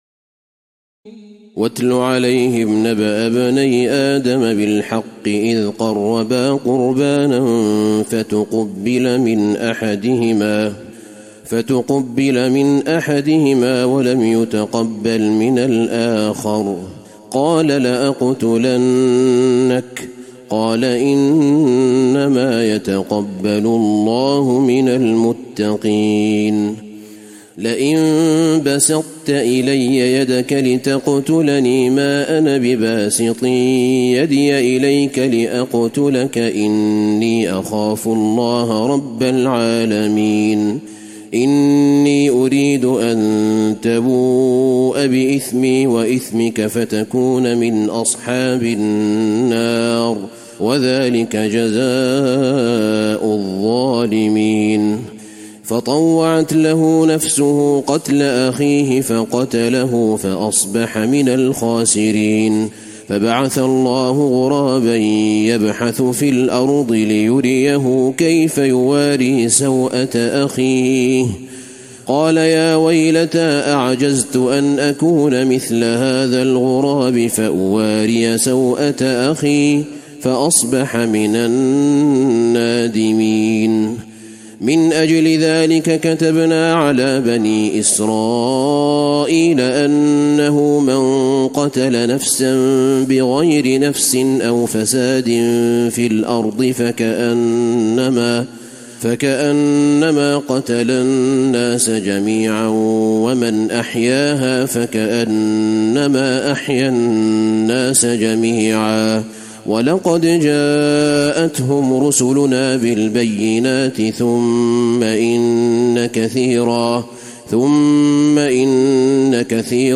تراويح الليلة السادسة رمضان 1435هـ من سورة المائدة (27-96) Taraweeh 6 st night Ramadan 1435H from Surah AlMa'idah > تراويح الحرم النبوي عام 1435 🕌 > التراويح - تلاوات الحرمين